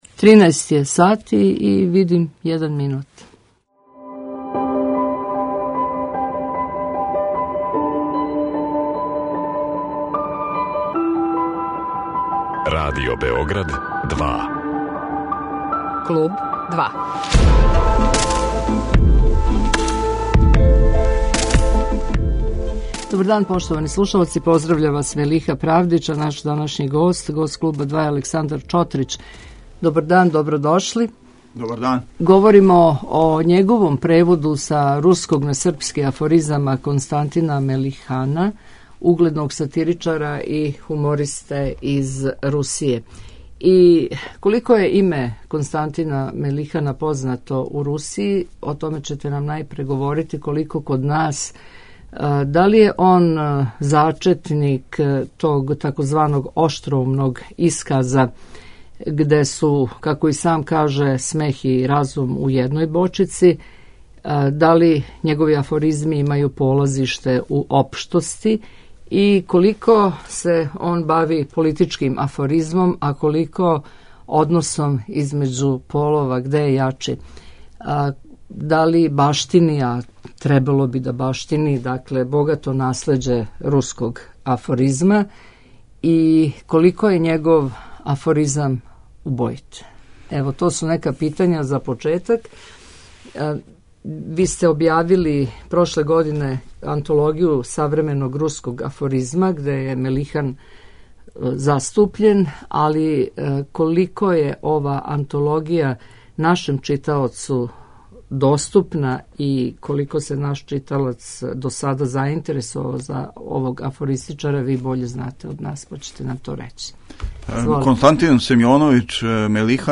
У данашњој емисији слушаћете и Мелиханове афоризме које ће читати његов преводилац, наш гост Александар Чотрић.